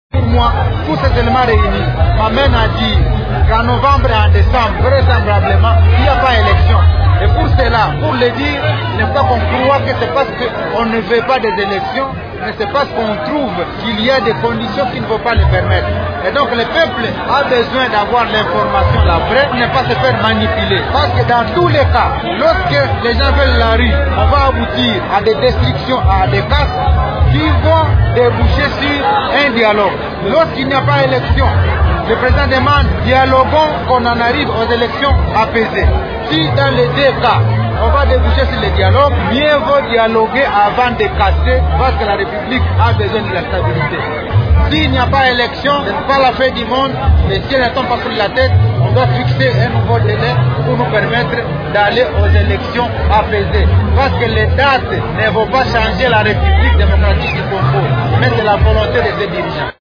« S’il n’y a pas élection, ce n’est pas la fin du monde. Le ciel ne va pas tomber sur la tête », a déclaré samedi 23 avril le gouverneur du Nord-Kivu, Julien Paluku, au cours d’une matinée politique de la Majorité présidentielle à l’occasion du 26e  anniversaire de l’instauration du multipartisme en RDC.
Vous pouvez écouter les explications de Julien Paluku.